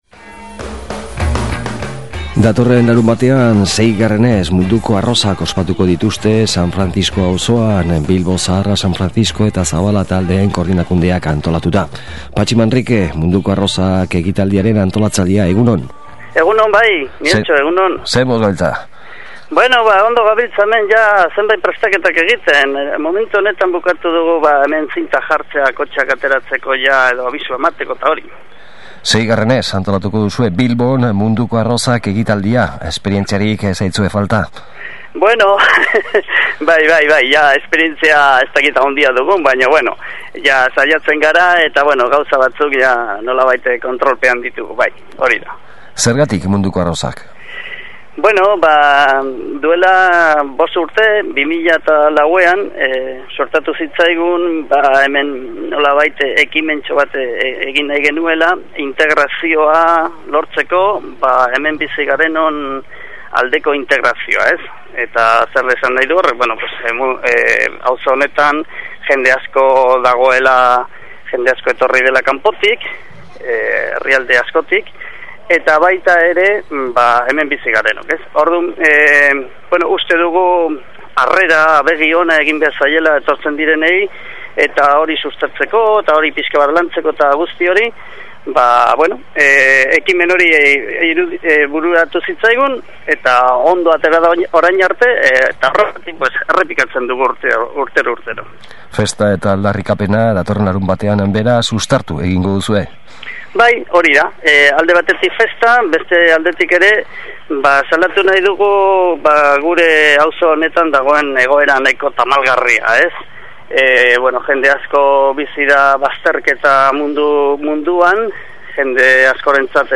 SOLASALDIA: UEUko Udako Ikastaroak Eibarren